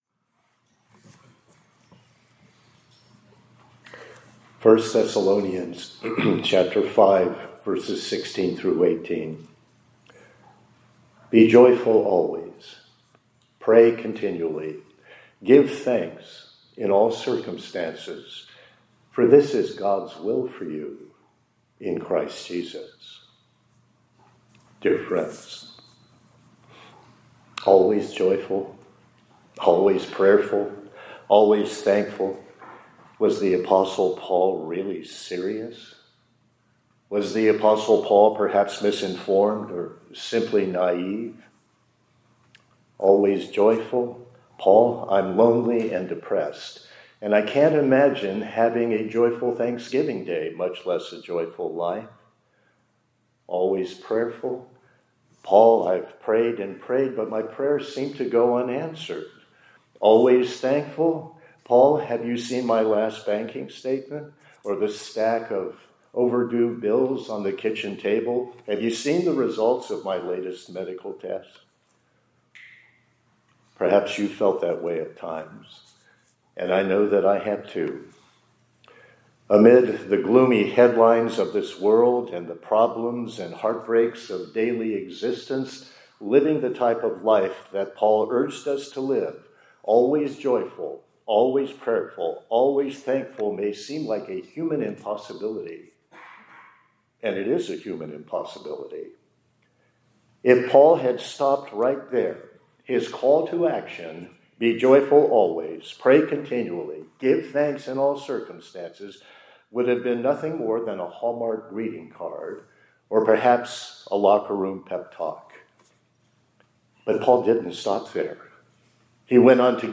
2025-11-19 ILC Chapel — Always Joyful, Prayerful, Thankful